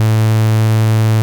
BUCHLA A2.#.wav